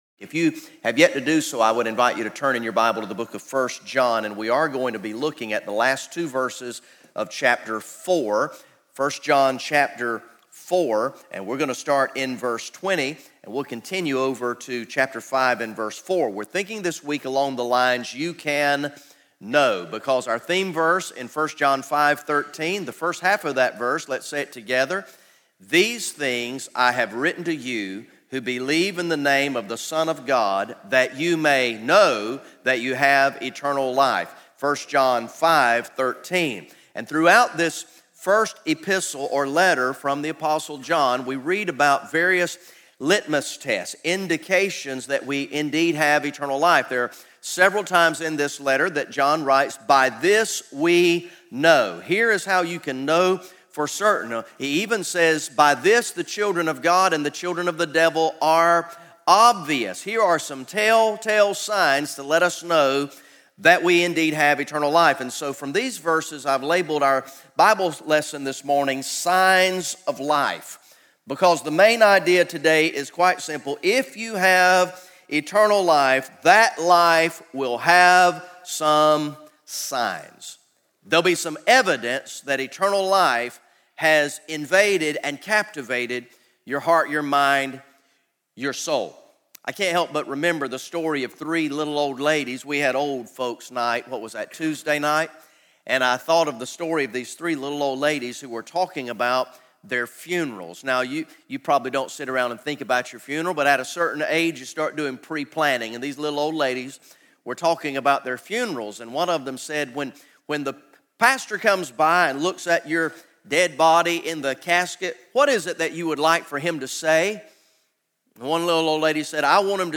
Message #09 from the ESM Summer Camp sermon series through the book of First John entitled "You Can Know"